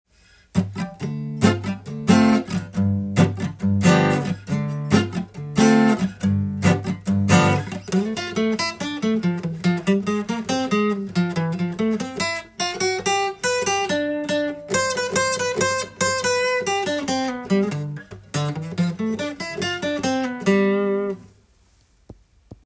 カンタンⅡ-Ⅴコード
Dm7/G7
全ての音源で適当に弾いているだけですが
③はジャズ定番のツーファイブと言われるコード進行です。